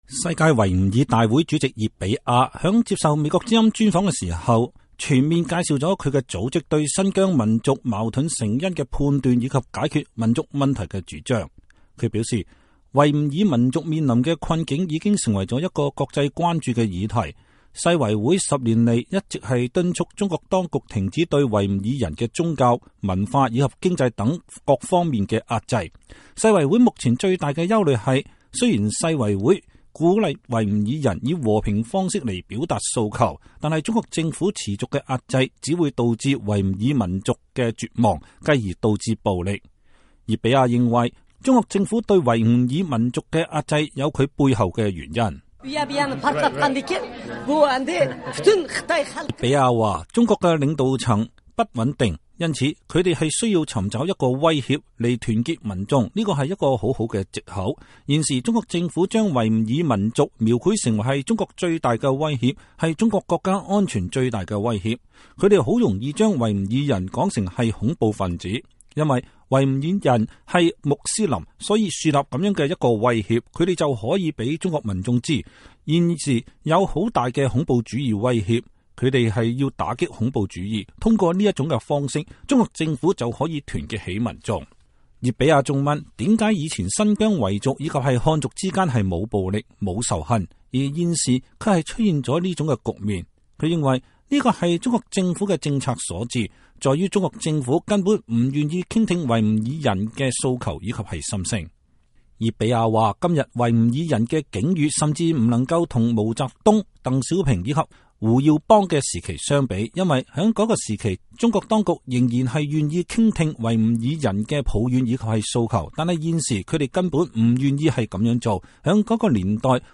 與會演講的世界維吾爾大會主席熱比婭·卡德爾女士接受美國之音中文部專訪時表示，世維會不會代表製造暴力的維吾爾極端分子。
世界維吾爾大會主席熱比婭女士在接受美國之音專訪的時候，全面介紹了她的組織對新疆民族矛盾成因的判斷和解決民族問題的主張。